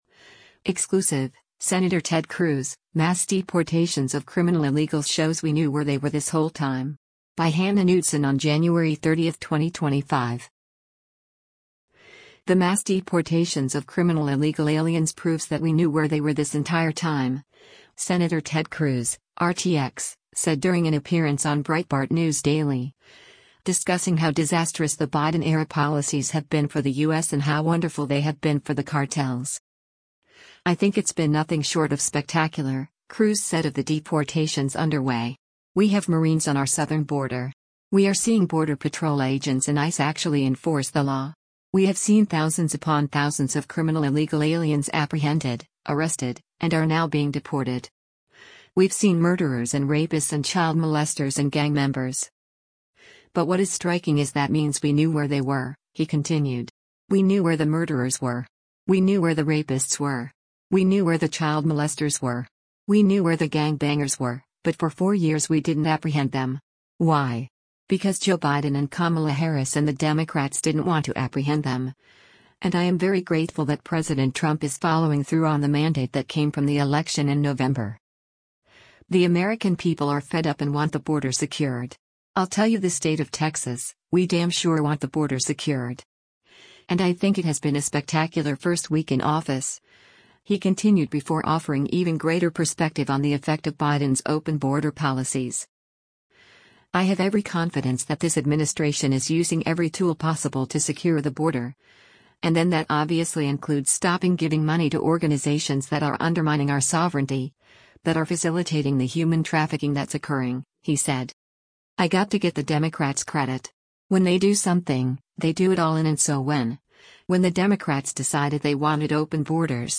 The mass deportations of criminal illegal aliens proves that we knew where they were this entire time, Sen. Ted Cruz (R-TX) said during an appearance on Breitbart News Daily, discussing how disastrous the Biden-era policies have been for the U.S. and how wonderful they have been for the cartels.